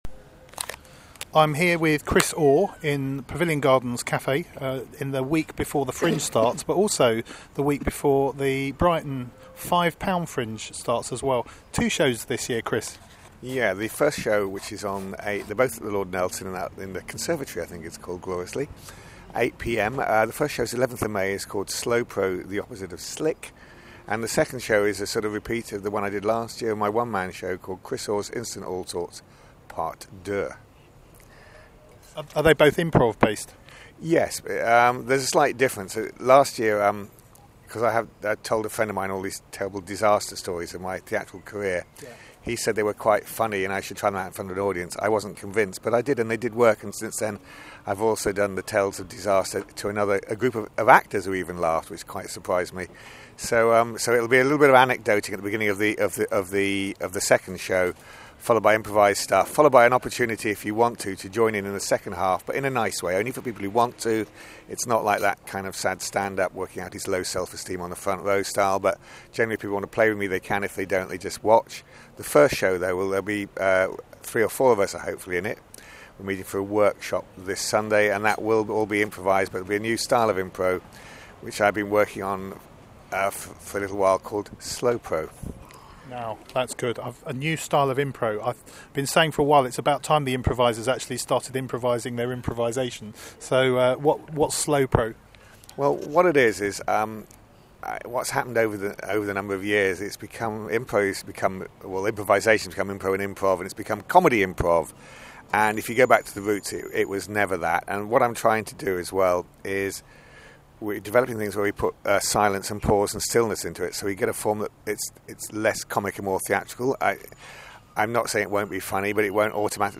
Audio Interviews, Brighton Fringe 2014